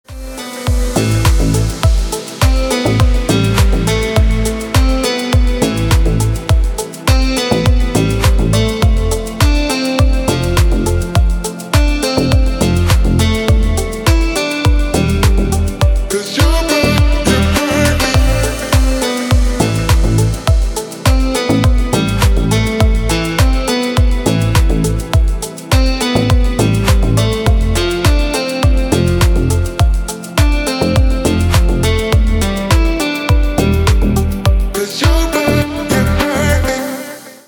Категория: Deep House рингтоны